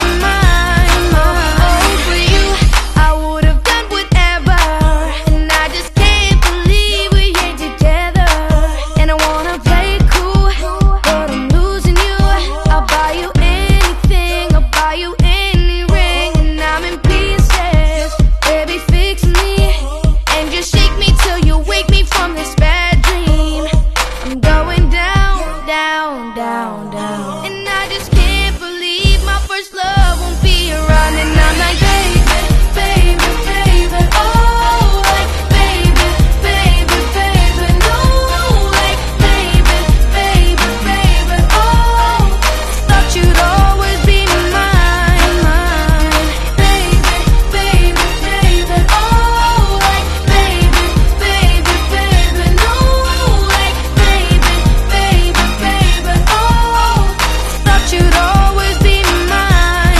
Baby sound effects free download